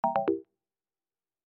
sfx_lose.mp3